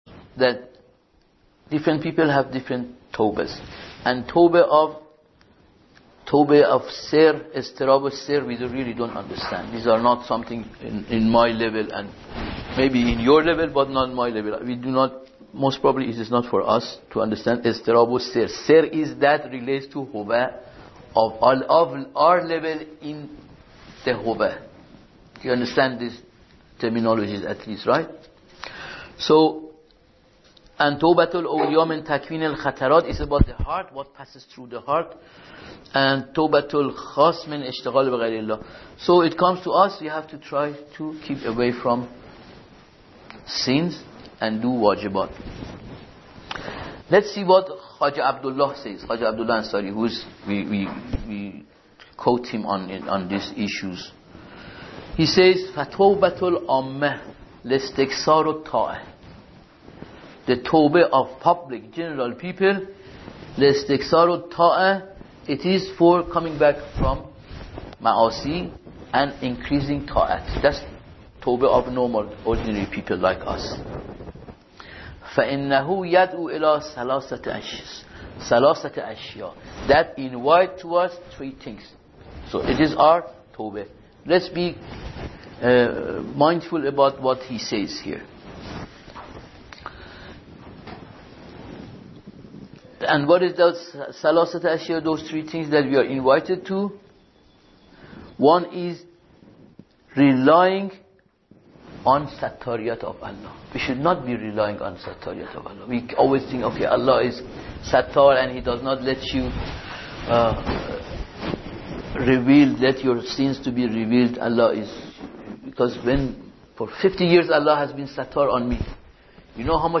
Spiritualizing Life-Practical:Lecture9